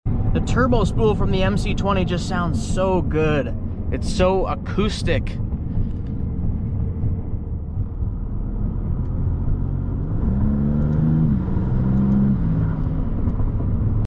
Maserati MC20 Turbo spool sounds